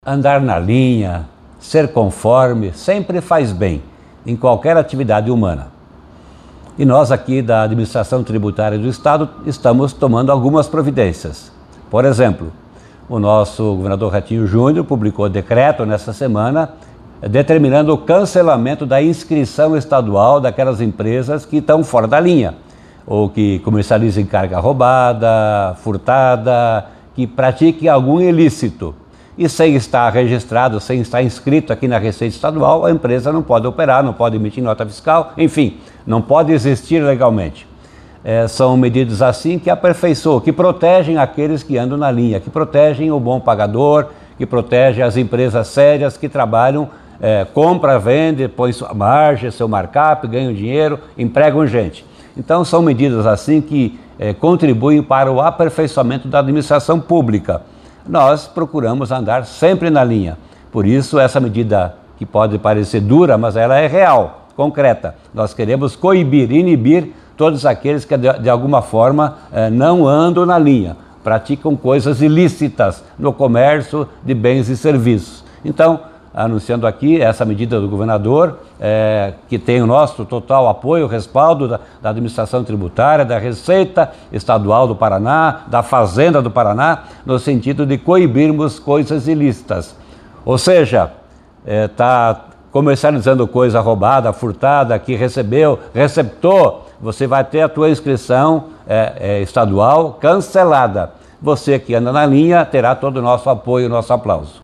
Sonora do secretário da Fazenda, Norberto Ortigara, sobre o decreto que cancela inscrição de empresas envolvidas com cargas roubadas